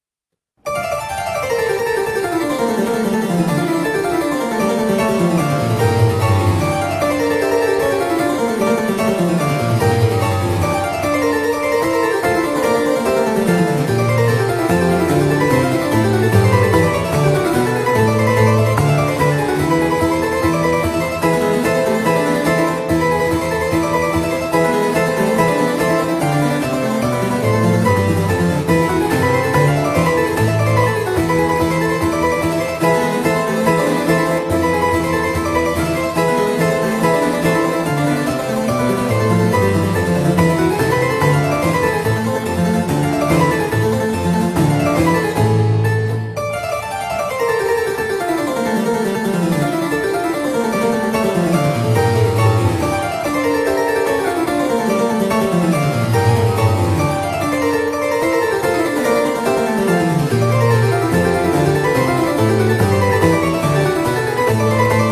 Απόσπασμα από τη Σονάτα του Domenico Scarlatti σε Ρε ελάσσονα σε τσέμπαλο, K.517